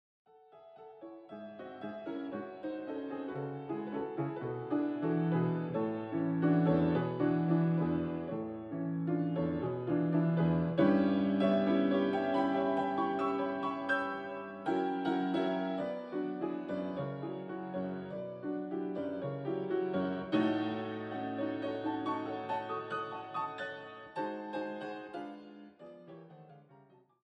👇エキエル版での解釈
エキエル版では、メロディ部が変わらず、内声のみが変化しています。
この繊細さ、長短が混在したような微妙なサウンドが、ポーランドらしさを感じます。